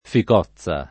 ficozza [ fik 0ZZ a ]